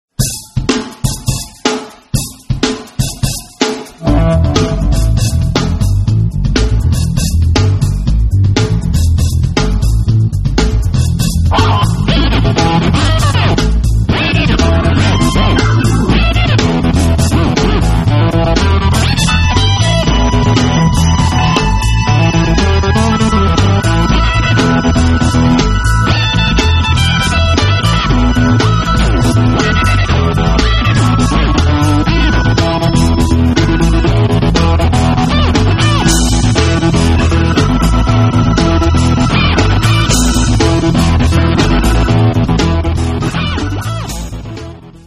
Funk
Fusion
Instrumental
Psychedelic
Rock